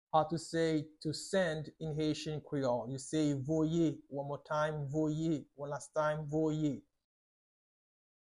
Listen to and watch “Voye” audio pronunciation in Haitian Creole by a native Haitian  in the video below:
26.How-to-say-To-send-in-Haitian-Creole-–-Voyewith-pronunciation.mp3